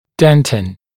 [ˈdentin][ˈдэнтин]дентин (твердая ткань зуба)